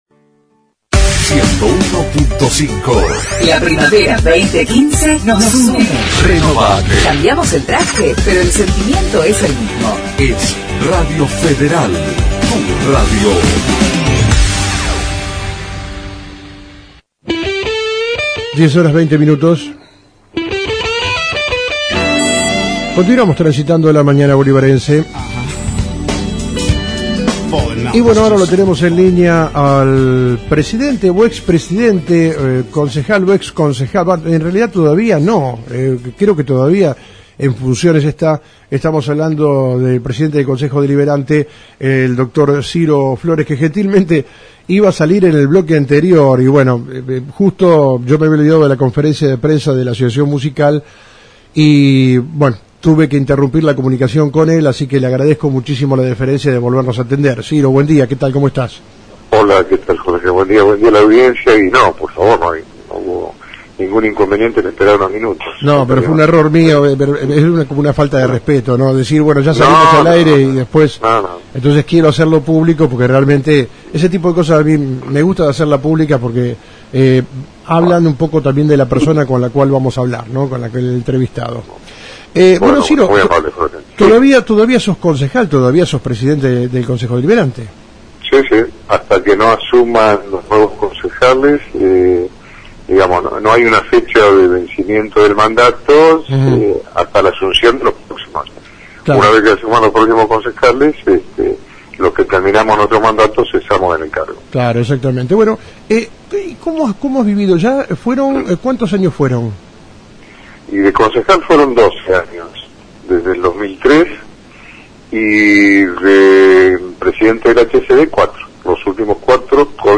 Ultima Sesión del Concejo Deliberante de Bolívar con los Concejales Salientes :: Radio Federal Bolívar
Presidente del Cuerpo Dr. Francisco Siro Flores